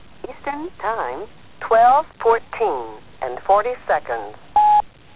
Registrazioni sonore di happening Fluxus